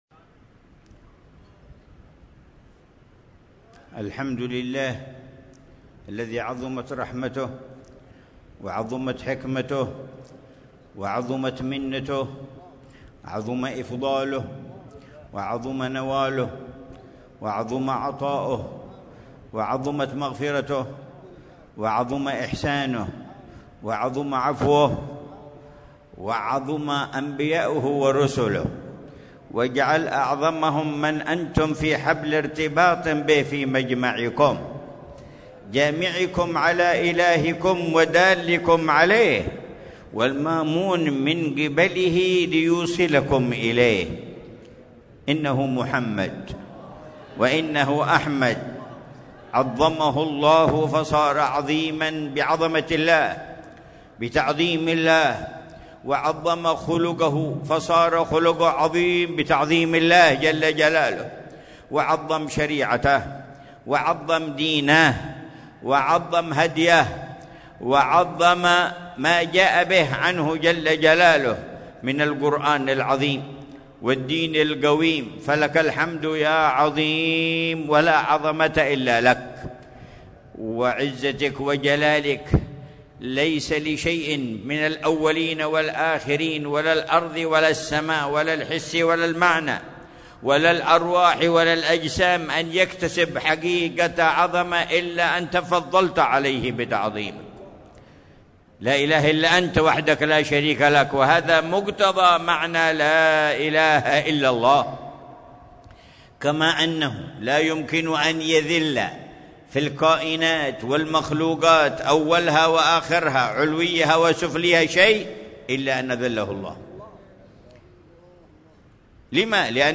مذاكرة العلامة الحبيب عمر بن محمد بن حفيظ في اختتام المجالس النبوية بمدينة الشحر، في مسجد الشيخ فضل بن عبد الله بن بافضل، ليلة الأربعاء 16 ربيع الثاني 1447هـ بعنوان: